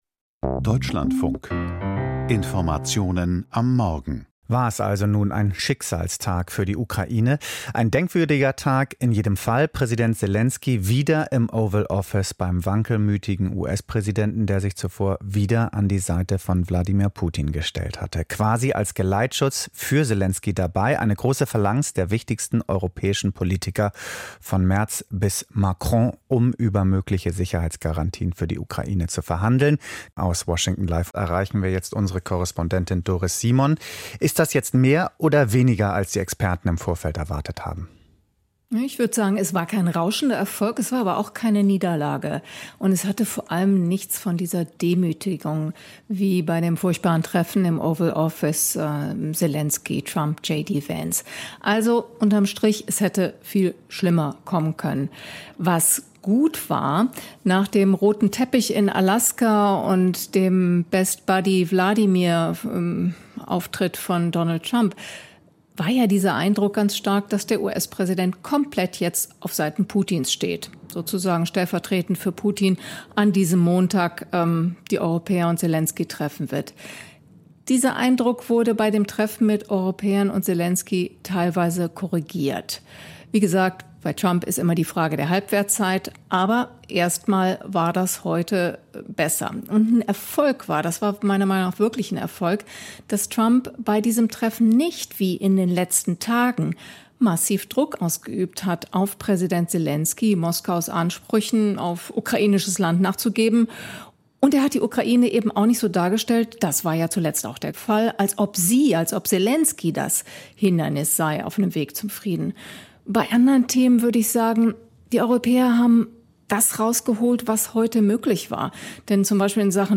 Die Europäer lernen, mit Trump umzugehen, findet US-Korrespondentin